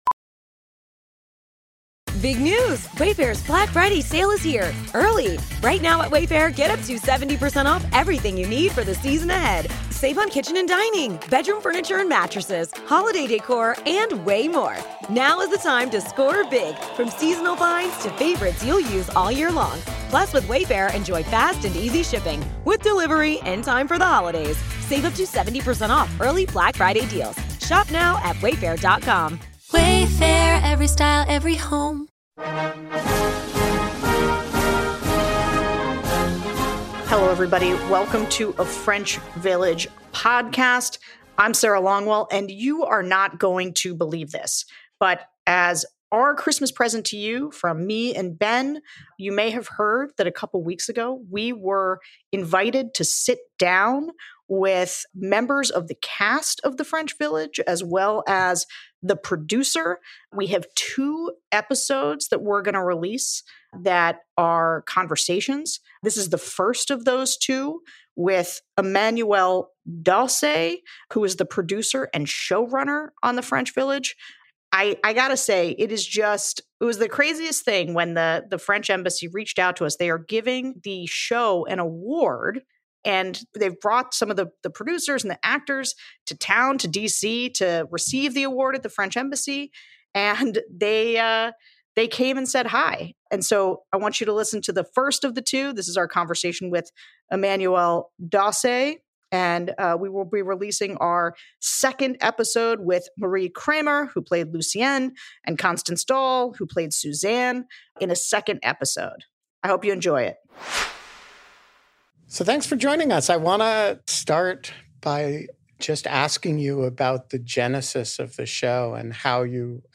Taped in-studio